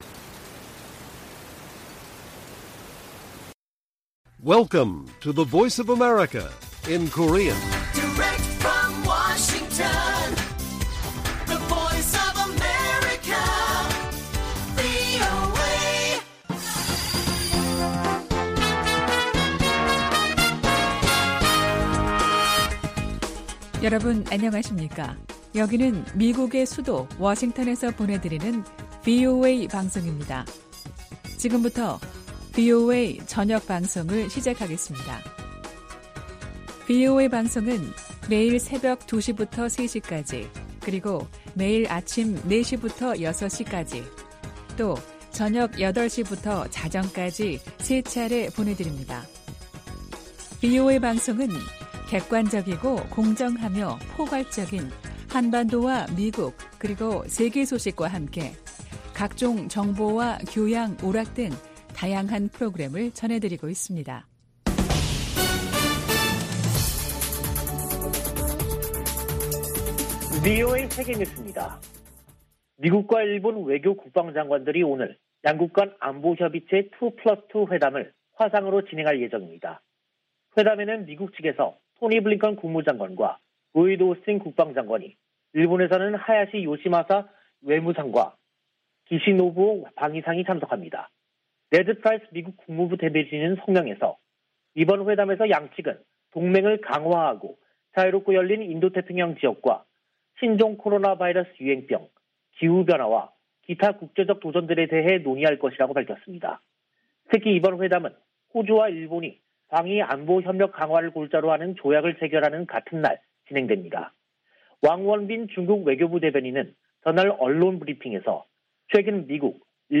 VOA 한국어 간판 뉴스 프로그램 '뉴스 투데이', 2022년 1월 6일 1부 방송입니다. 북한은 5일 쏜 단거리 발사체가 극초음속 미사일이었다고 밝혔습니다. 토니 블링컨 미국 국무장관이 북한의 새해 첫 미사일 도발을 규탄했습니다. 1월 안보리 의장국인 노르웨이는 북한의 대량살상무기와 탄도미사일 개발에 우려를 표시했습니다.